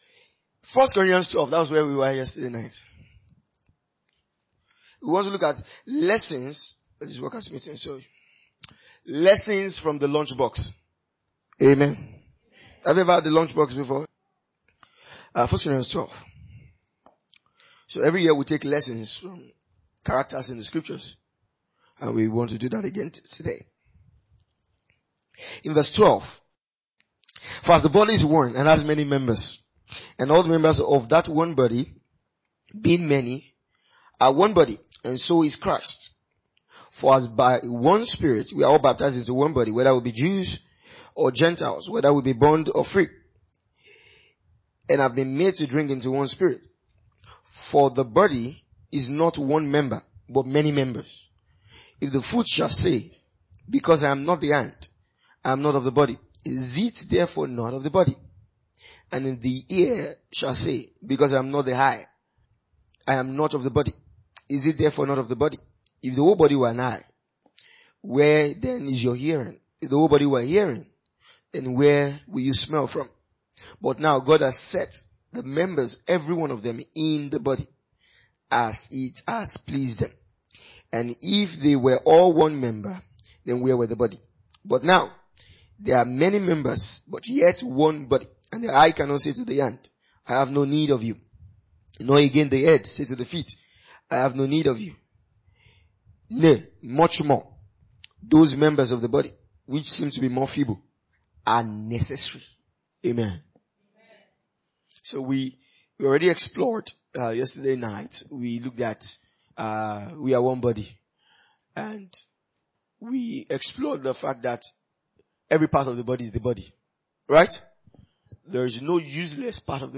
A teaching from our annual workers’ meeting. We reflect on the story of the young boy in John 6:8-13, whose small lunch of five loaves and two fish was multiplied by Jesus to feed 5,000. This message underscores that every believer’s role in the local church, no matter how small it seems, is vital to fulfilling God’s will.